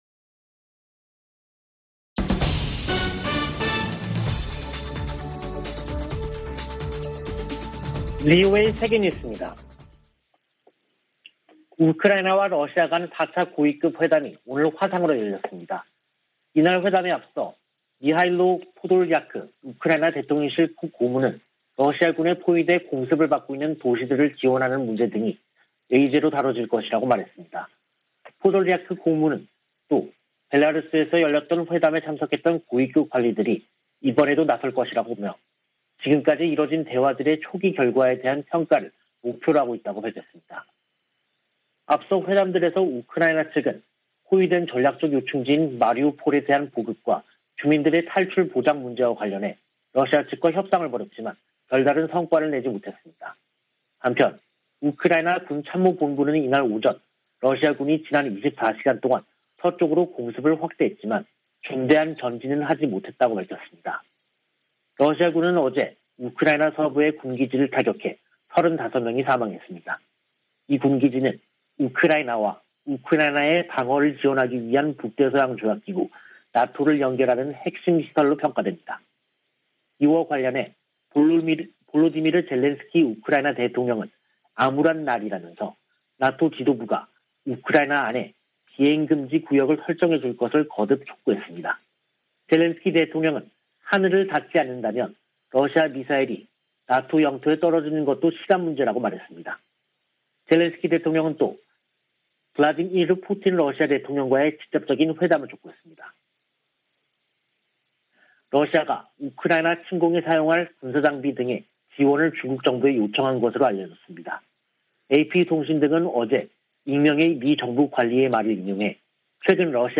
VOA 한국어 간판 뉴스 프로그램 '뉴스 투데이', 2022년 3월 14일 3부 방송입니다. 성 김 미 대북특별대표가 중국에 북한이 도발을 중단하고 대화에 복귀하도록 영향력을 발휘해 달라고 요구했습니다. 미 국무부는 북한 탄도미사일 발사 등이 역내 가장 긴박한 도전이라고 지적하며 한국 차기 정부와의 협력을 기대했습니다. 미-한 군 당국은 북한이 신형 ICBM 성능 시험을 위한 추가 발사를 준비하는 징후를 포착하고 대비태세를 강화하고 있습니다.